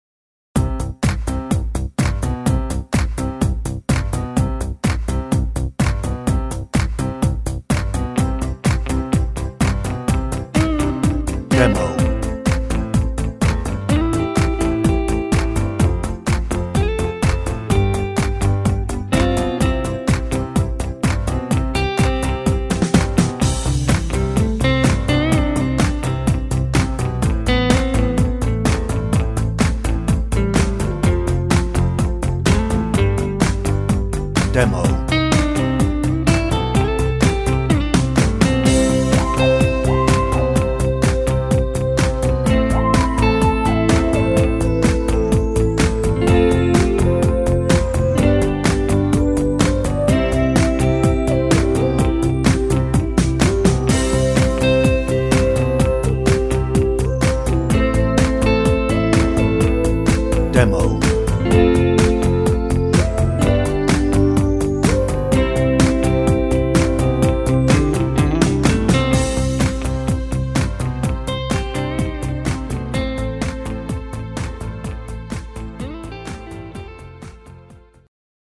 Hoedown
No ref vocal
Instrumental